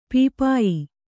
♪ pīpāyi